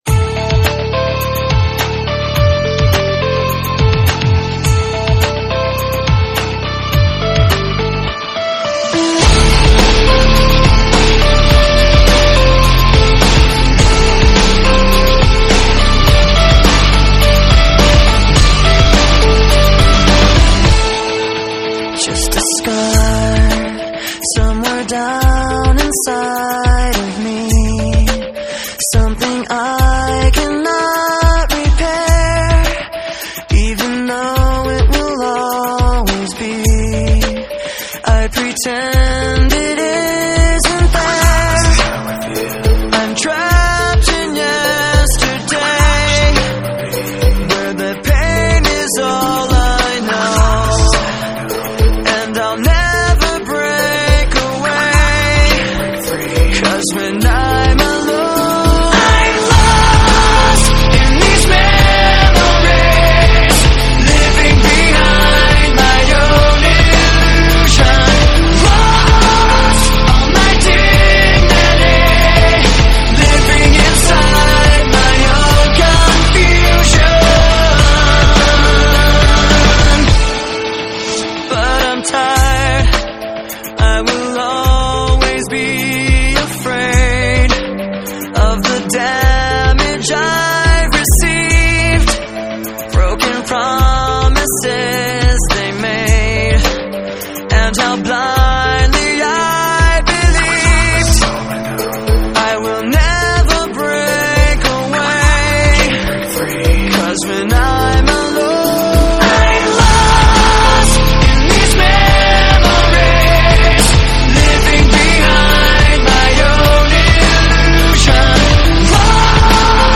Nu Metal, Alternative Rock